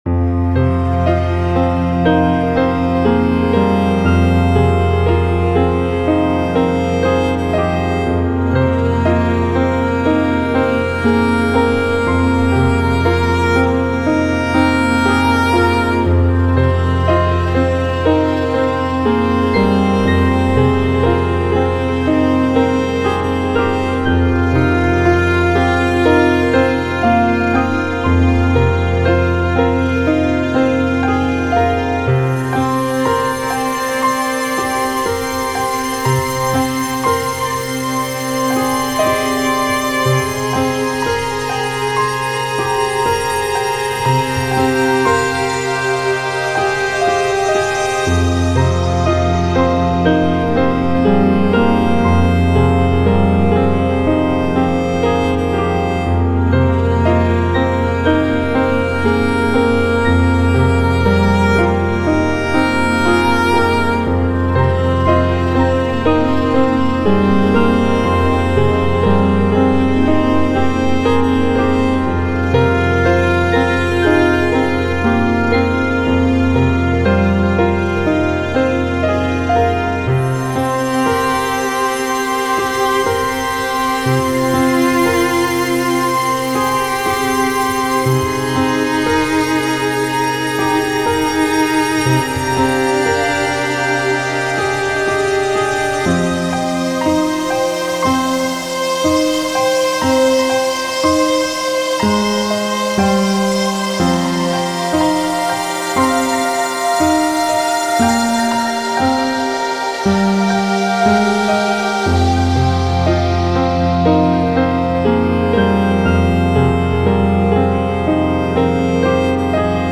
Rescue (Electronic Chamber Music)